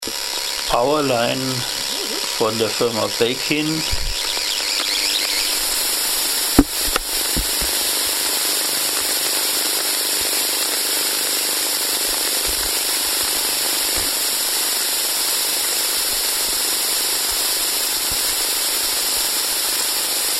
STÖRQUELLEN AUDIODATENBANK
Powerline Belkin F5D4079 Code: 944452CA6B47 Low E-Field Netz im Betrieb 100-149